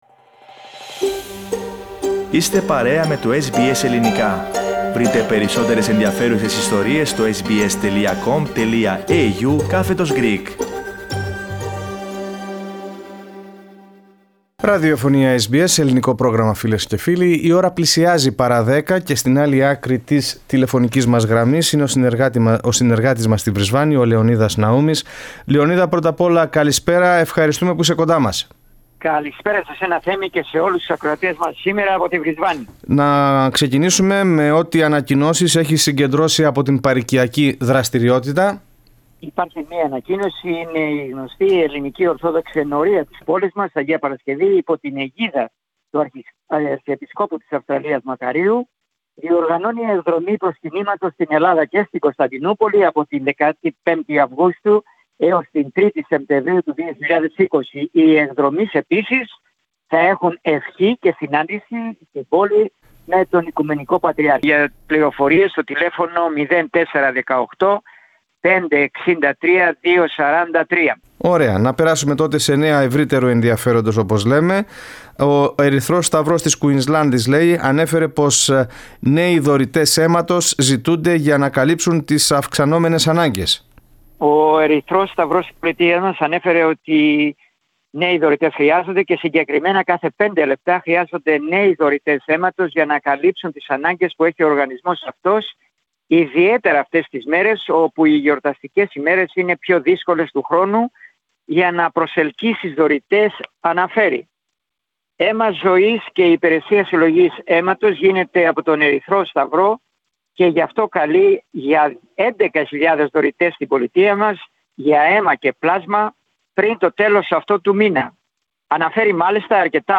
Πολλά και ενδιαφέροντα τα νέα από την Κουηνσλάνδη, στην εβδομαδιαία ανταπόκριση.